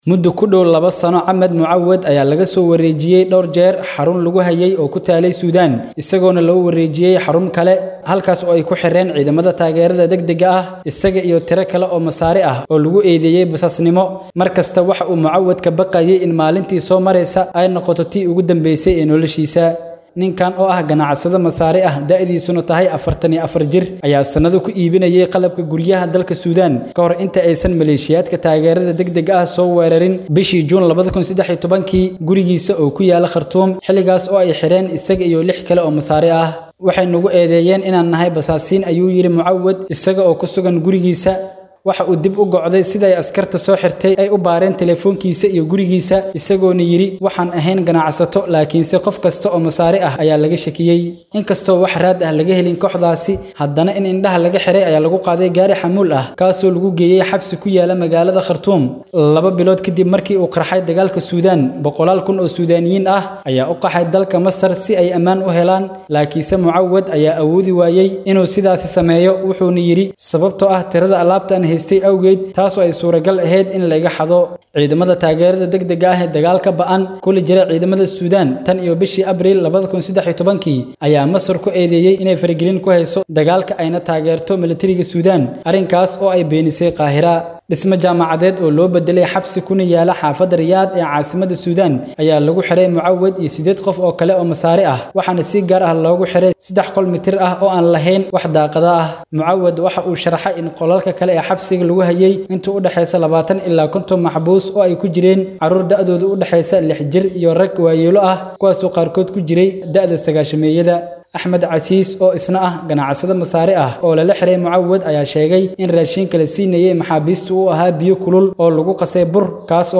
warbixin-xabsiyada-suudaan.mp3